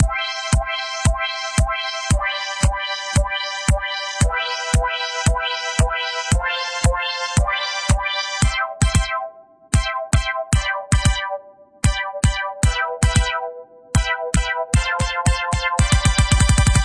[パターン３, ４]：フィルター・・・
今度はトラック２のフィルター（LP）のカットオフ・スライダーにサイドチェイン設定をして、 前半はキックドラムが鳴ったと同時にフィルターが閉じるように設定。
後半はキックドラムが鳴ったと同時にフィルターが開くように設定。キックドラムのリズムも変えて遊んでいます。
Side-chain-test-3.mp3